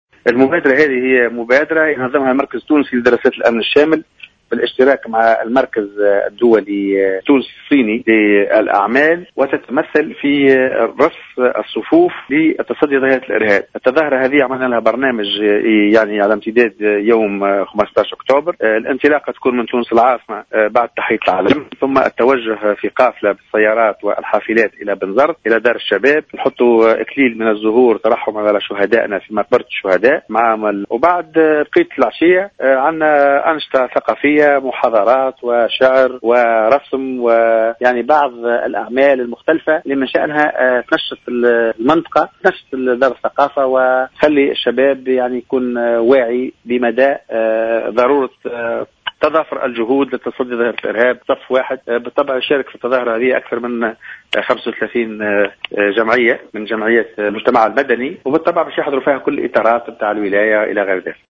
في تصريح للجوهرة "اف ام"